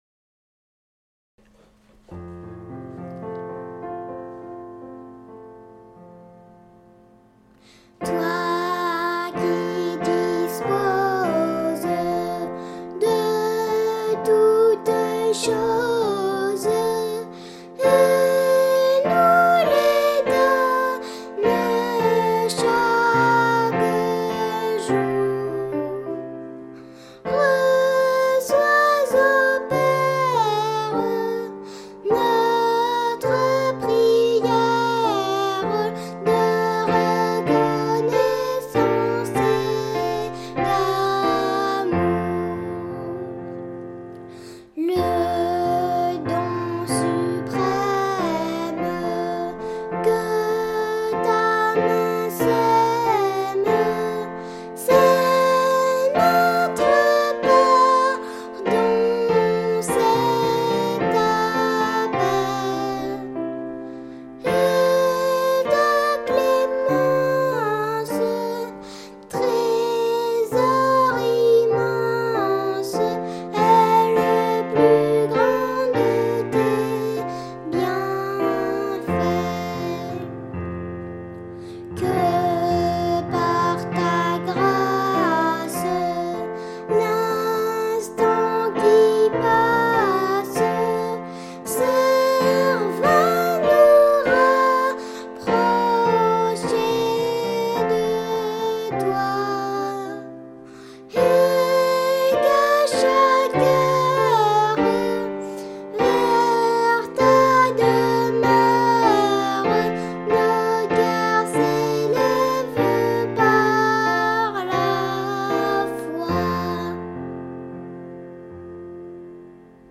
Un nouveau cantique : Toi qui disposes.